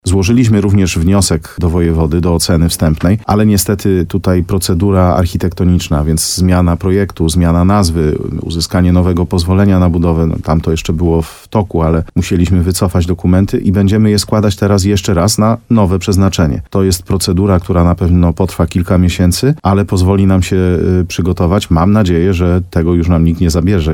– Tak jak w poprzednim pomyśle, ŚDS umieszczony zostanie w zabytkowym budynku dawnej Szkoły Podstawowej nr 2 w Łużnej – przekazał wójt Mariusz Tarsa w programie Słowo za Słowo w radiu RDN Nowy Sącz.